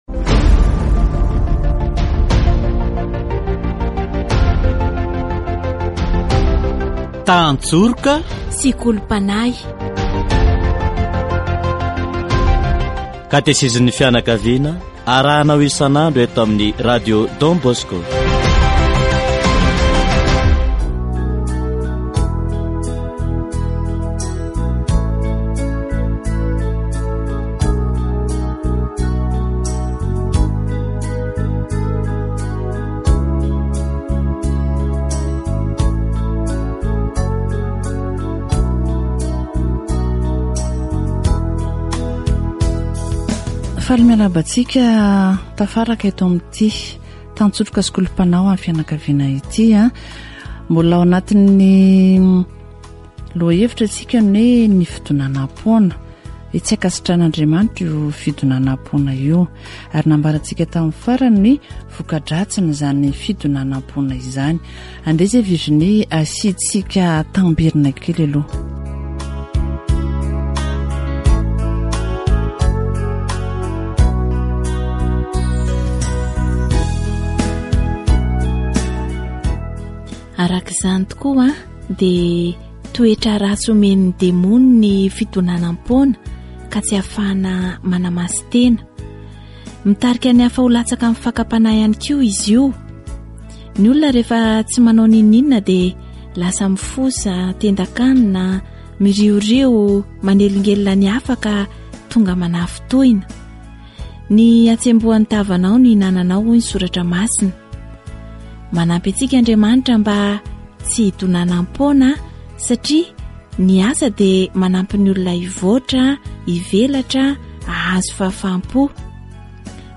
Catéchèse sur l'Oisiveté